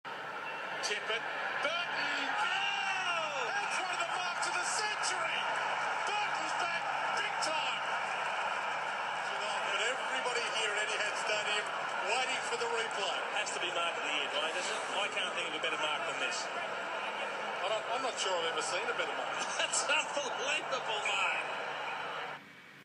Excited TV commentators as Brett "Birdman" Burton takes the Mark of the Year in 2009